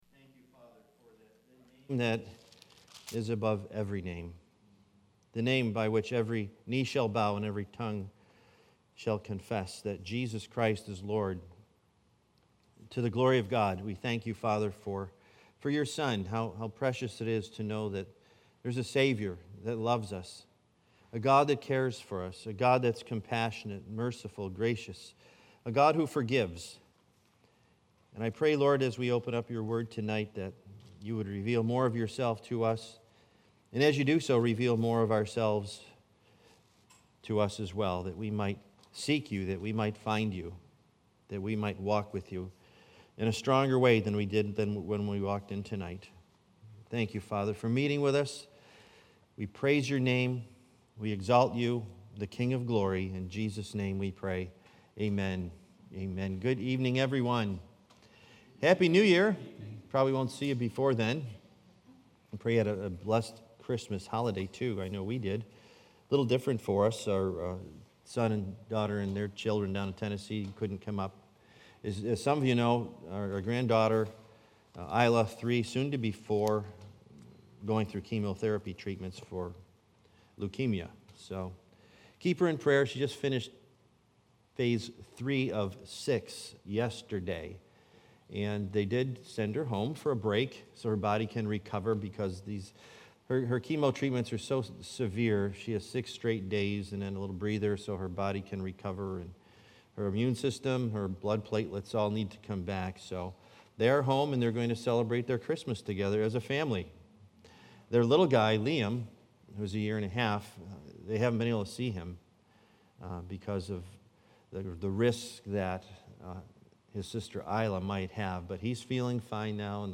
Series: Wednesday Bible Study